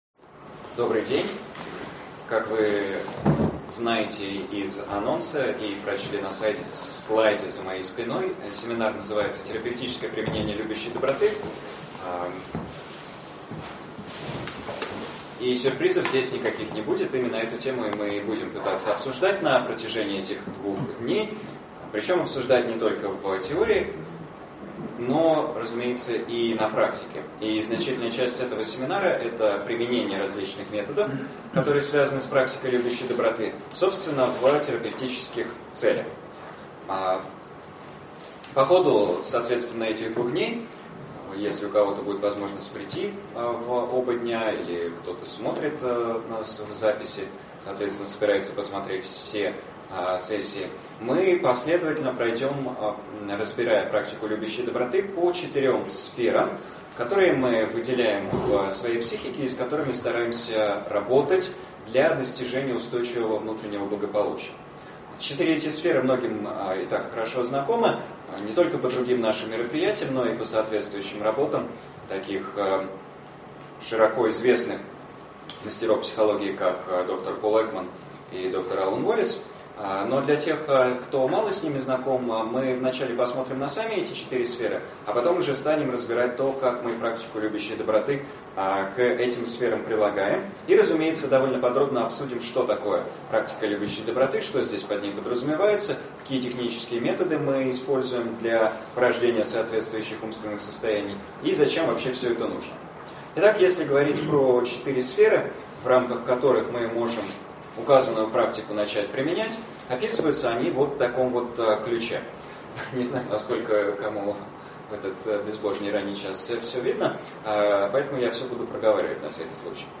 Аудиокнига Терапевтическое применение практики любящей доброты. Часть 1 | Библиотека аудиокниг